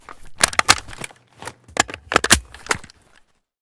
aek971_reload.ogg